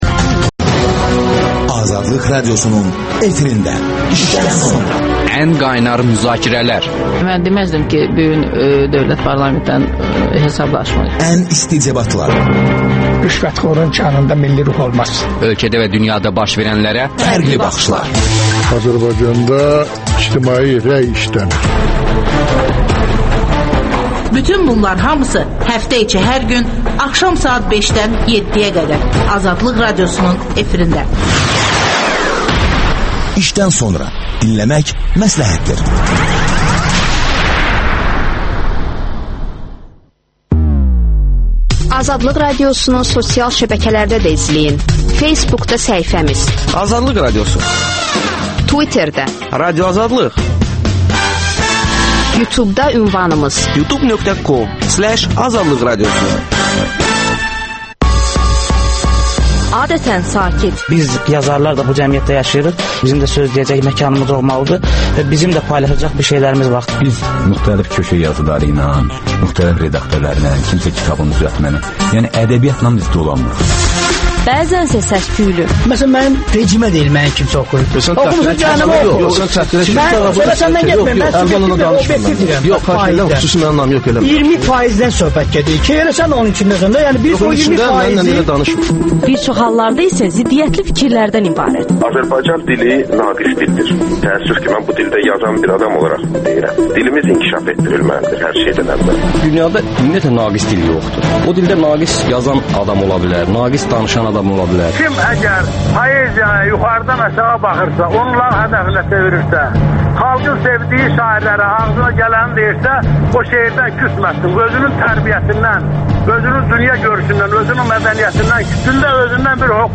İşdən sonra - İsrailin Azərbaycandakı səfiri ilə söhbət
Mixael Lotem suallara cavab verir.